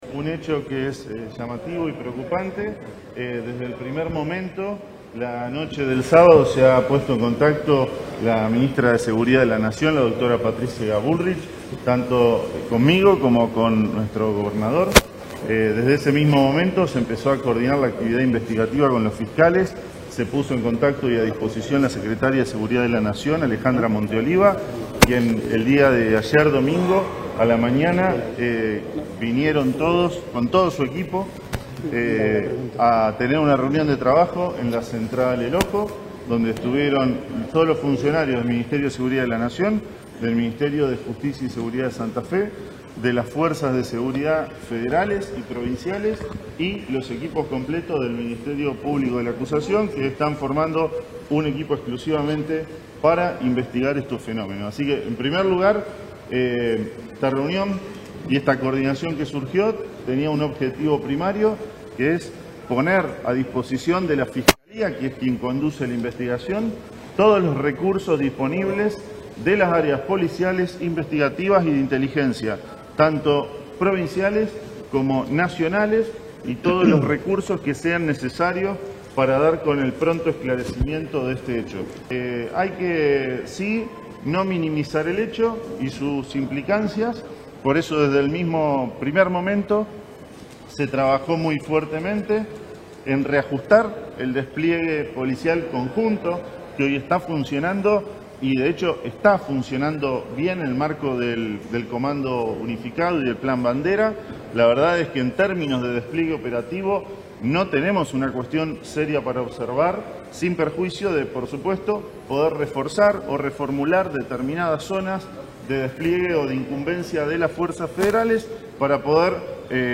La conferencia se desarrolló en la sede Centro de Justicia Penal, encabezada por el ministerio de Justicia y Seguridad de la provincia, Pablo Cococcioni, acompañado de la secretaria de Seguridad de la Nación, Alejandra Monteoliva; el fiscal Regional interventor, Matías Merlo; y el fiscal de Homicidios, Alejandro Ferlazzo.
Declaraciones de Cococcioni, Monteoliva, Merlo y Ferlazzo